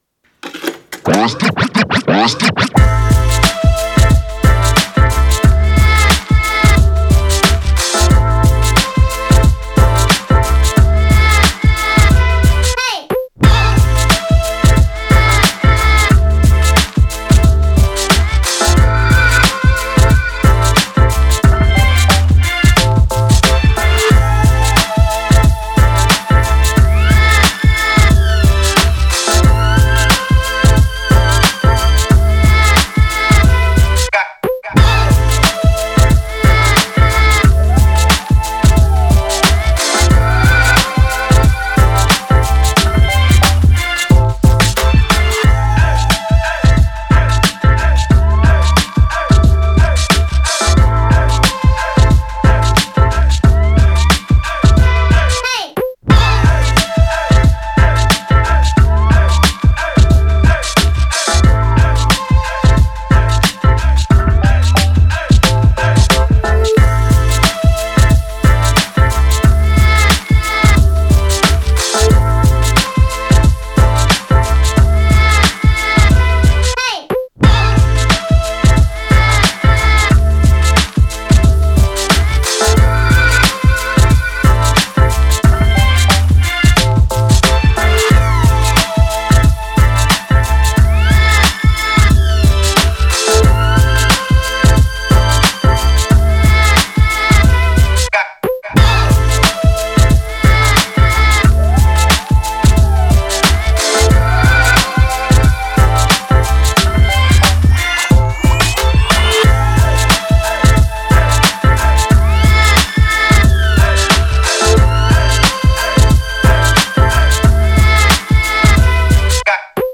Genre: chillout, hiphop.